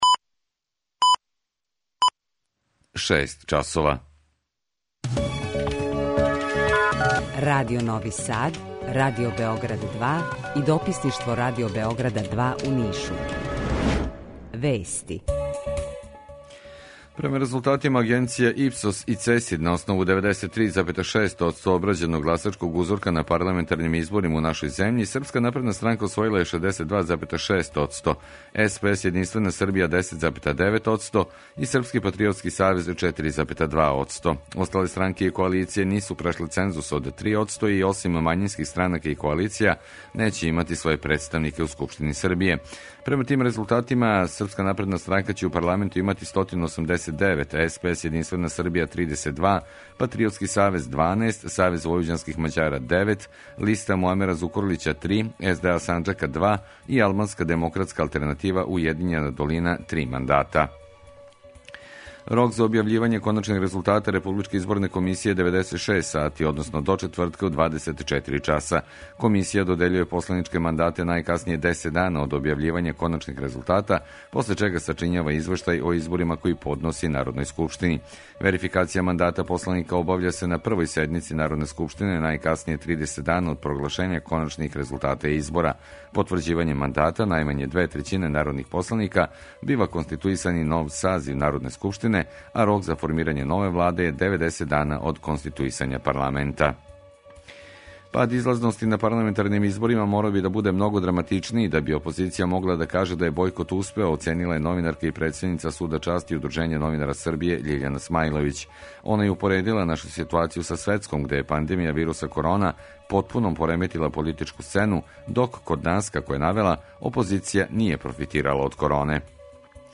Јутарњи програм из три студија